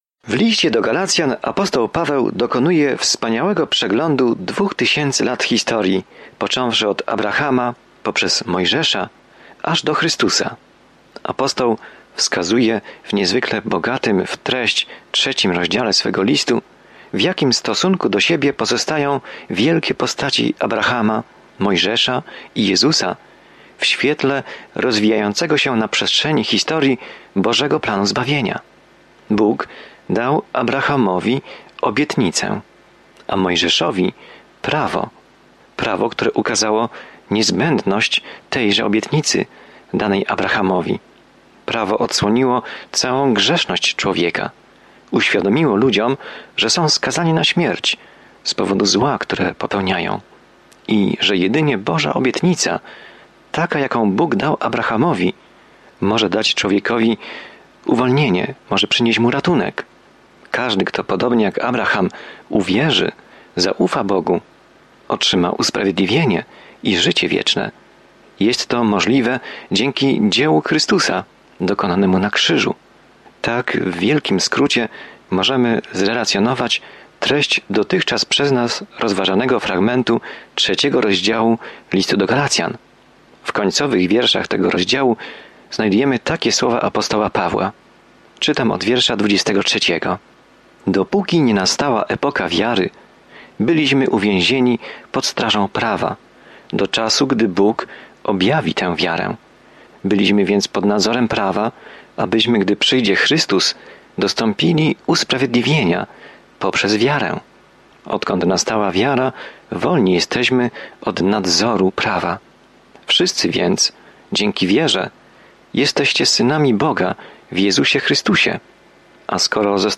Pismo Święte Galacjan 3:23-28 Dzień 9 Rozpocznij ten plan Dzień 11 O tym planie „Tylko przez wiarę” jesteśmy zbawieni, a nie przez cokolwiek, co czynimy, by zasłużyć na dar zbawienia – takie jest jasne i bezpośrednie przesłanie Listu do Galacjan. Codzienna podróż przez Galacjan, słuchanie studium audio i czytanie wybranych wersetów słowa Bożego.